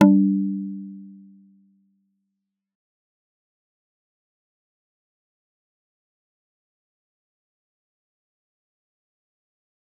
G_Kalimba-G3-f.wav